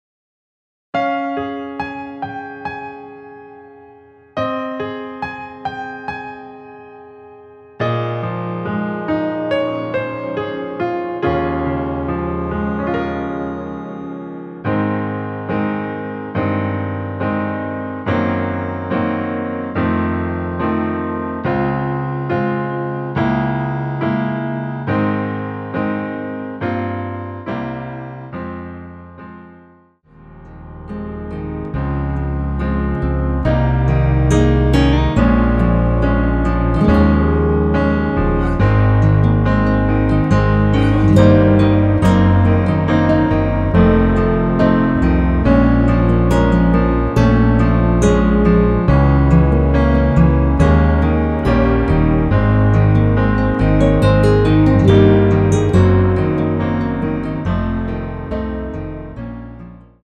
원키에서(-2) 내린 MR 입니다.
Ab
◈ 곡명 옆 (-1)은 반음 내림, (+1)은 반음 올림 입니다.
앞부분30초, 뒷부분30초씩 편집해서 올려 드리고 있습니다.